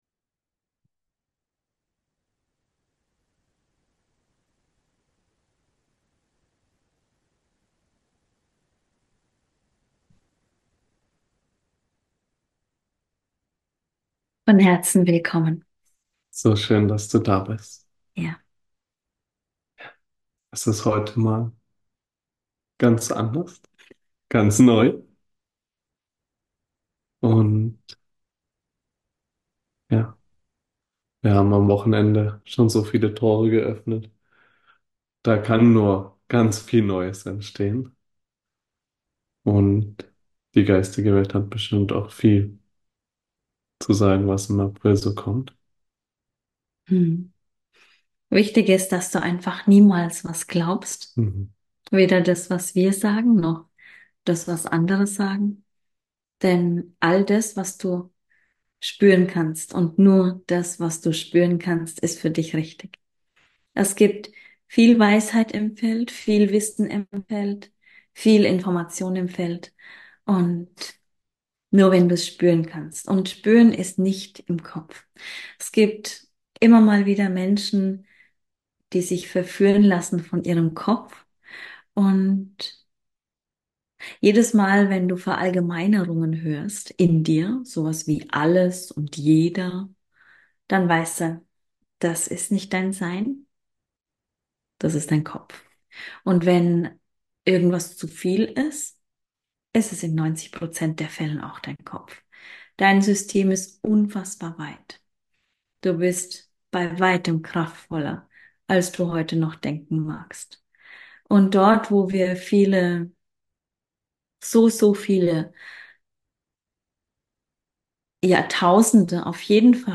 Live aus dem kristallinen GitterNetz - Heilung für dich.